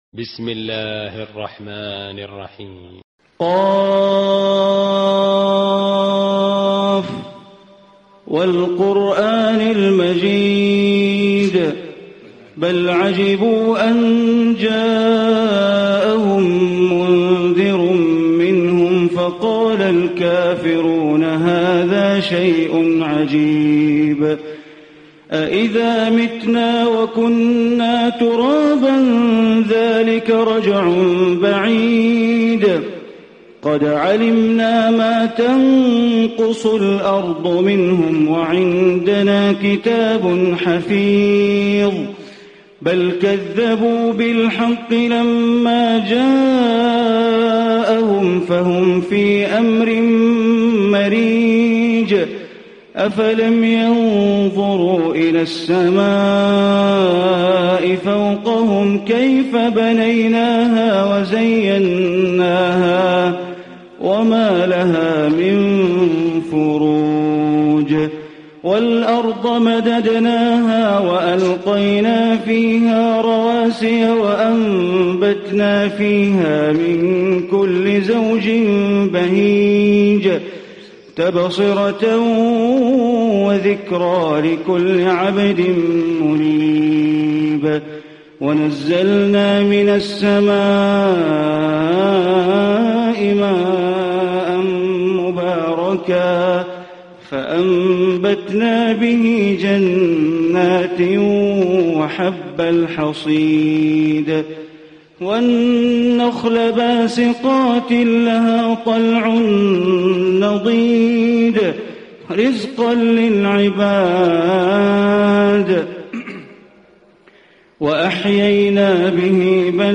Surah Qaf Recitation by Sheikh Bandar Baleela
Surah Qaf, listen online mp3 tilawat / recitation in Arabic recited by Imam e Kaaba Sheikh Bandar Baleela.